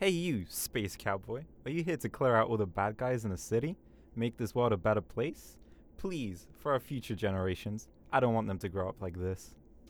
Voice Lines / Street barklines
Update Voice Overs for Amplification & Normalisation
hey you space cowboy are you here to.wav